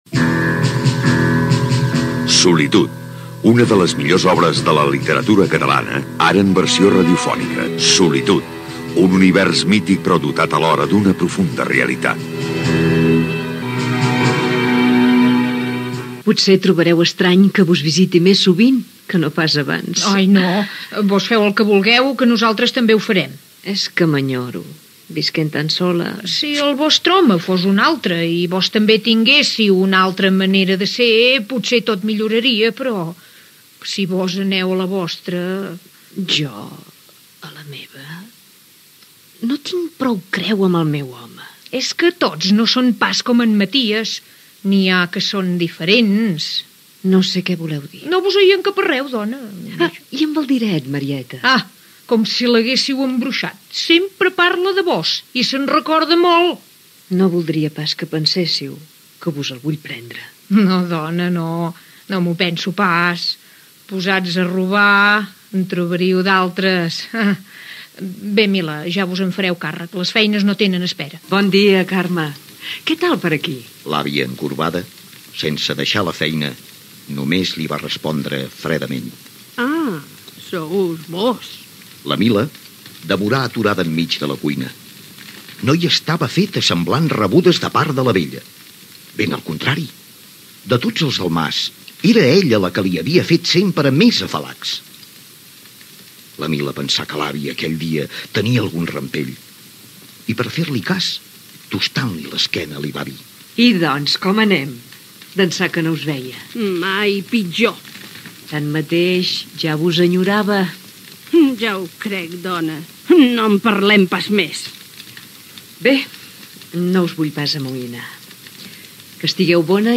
Careta del programa, fragment de l'adaptació radiofònica de l'obra "Solitud" de Víctor Català (Caterina Albert).
Ficció